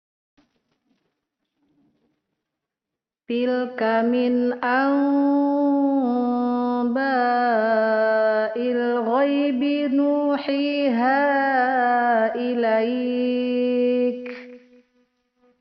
Al-Jauf merupakan temat keluarnya huruf-huruf Mad, yaitu:
1.  ا (Alif) yang didahului dengan harokat Fathah ( َ  ) Membuka mulut dengan sempurna.
2.  ي (Yaa sukun) yang didahului dengan harokat Kasrah ( ِ  ) Menurunkan bibir bagian bawah.
3.  ؤ (Wau sukun) yang didahului dengan harokat Dhammah ( ُ  ) Memonyongkan dua bibir.